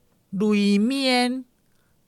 臺灣客語拼音學習網-進階學習課程-詔安腔-第九課